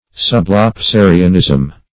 Sublapsarianism \Sub`lap*sa"ri*an*ism\, n.